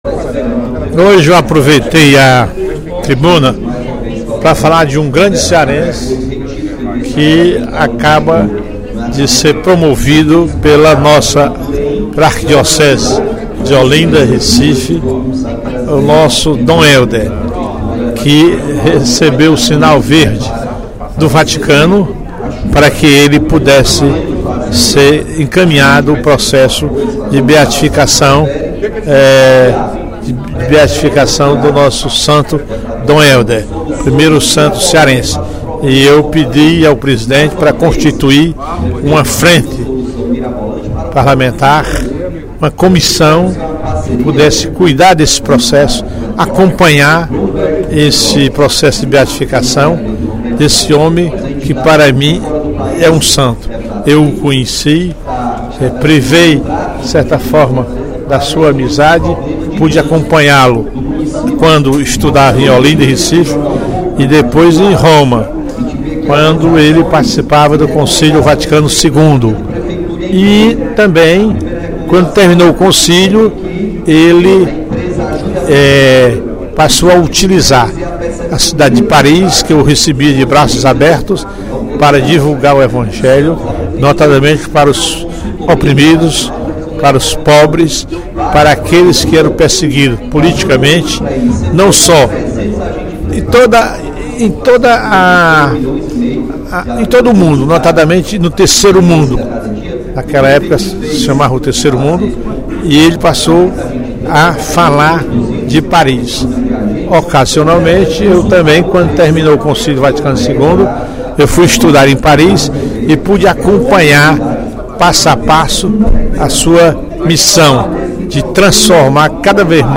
O deputado Professor Teodoro (PSD) anunciou, durante o primeiro expediente da sessão plenária desta quarta-feira (15/04), requerimento de sua autoria e do deputado Fernando Hugo (SD) solicitando a criação, na Assembleia, de uma comissão de acompanhamento do processo de beatificação e canonização do cearense Dom Hélder Câmara.